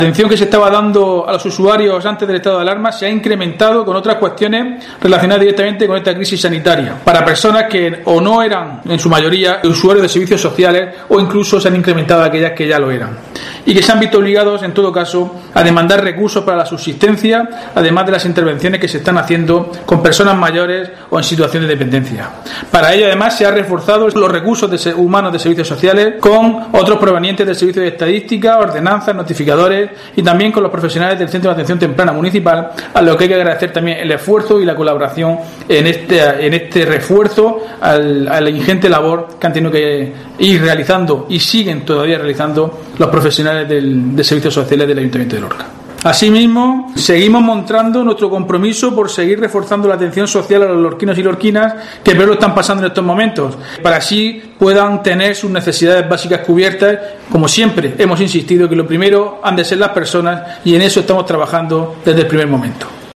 Diego José Mateos, alcalde de Lorca sobre Servicios Sociales